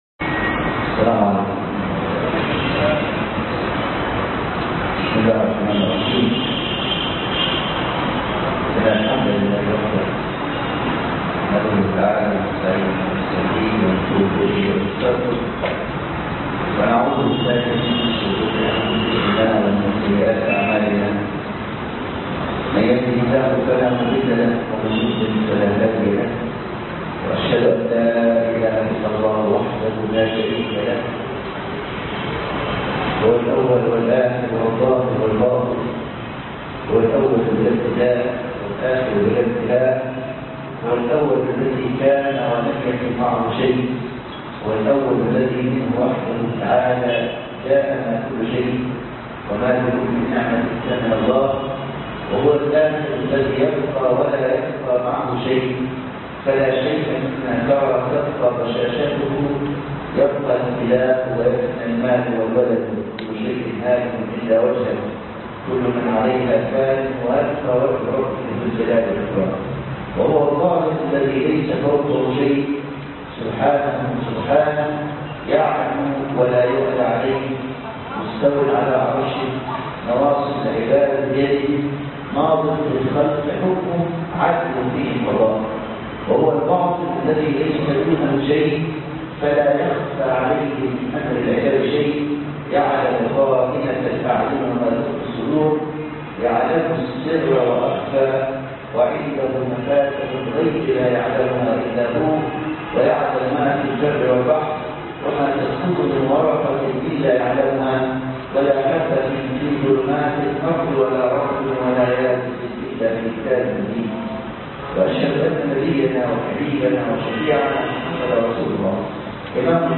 الدرس الأول (ومن الناس من يعبد الله على حرف)